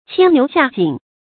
牽牛下井 注音： ㄑㄧㄢ ㄋㄧㄨˊ ㄒㄧㄚˋ ㄐㄧㄥˇ 讀音讀法： 意思解釋： 比喻事情棘手，很難辦到。